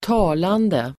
Uttal: [²t'a:lande]